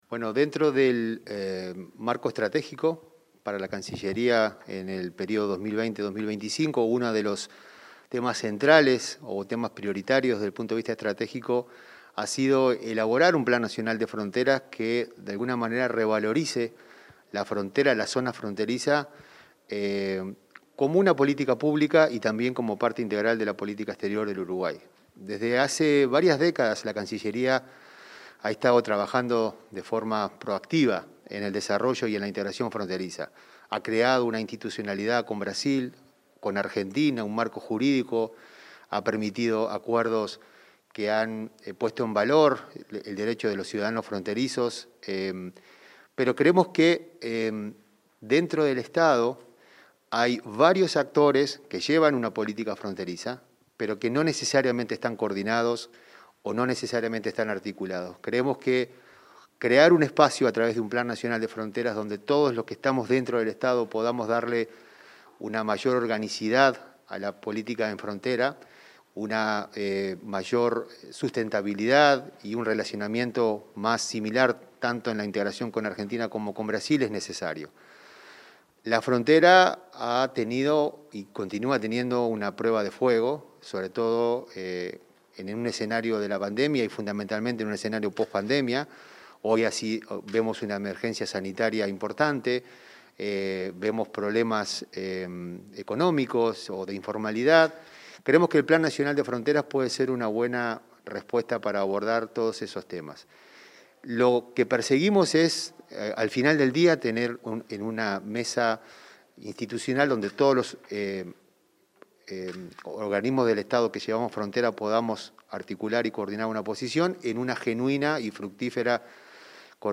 Entrevista al director Federico Perazza sobre el Plan Nacional de Frontera